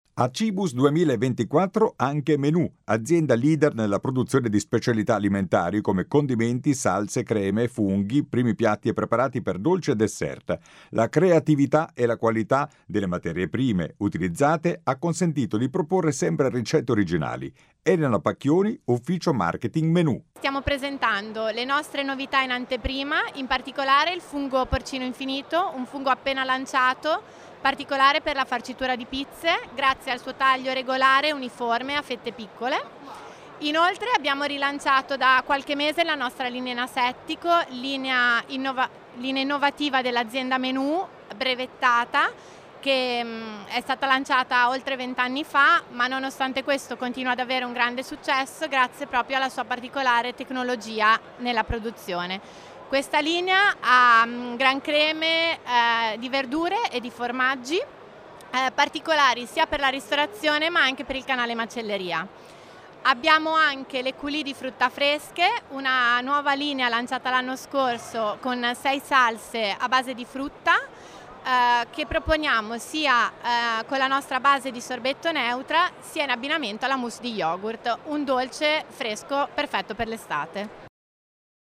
Qui di seguito le dichiarazioni raccolte dal nostro inviato sul posto